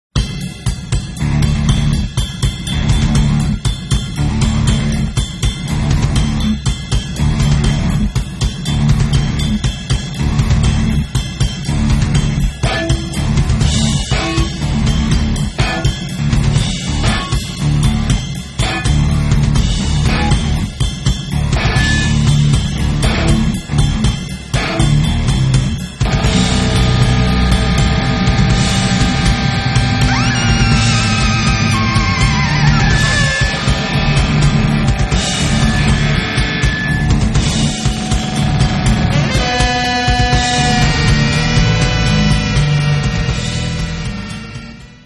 Fusion
Jazz
Rock